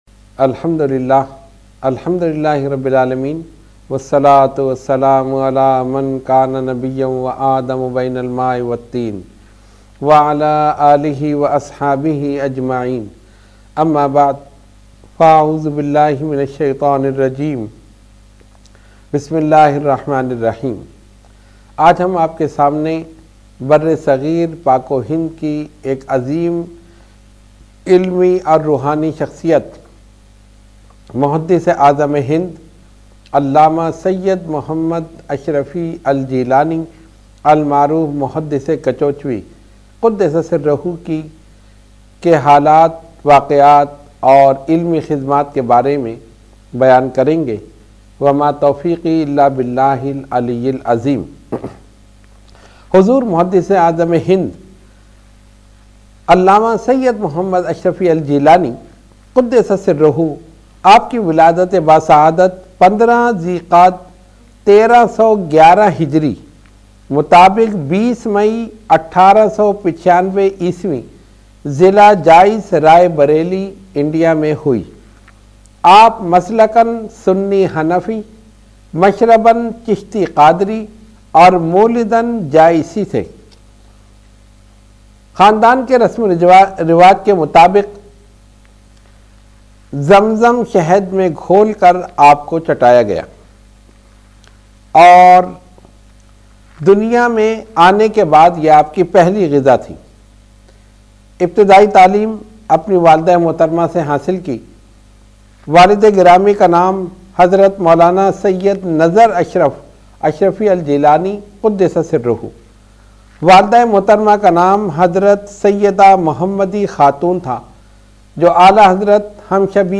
Roohani Tarbiyati Nashist held at Dargah Aliya Ashrafia Ashrafia Ashrafabad Firdous Colony Gulbahar Karachi.
Category : Speech | Language : UrduEvent : Weekly Tarbiyati Nashist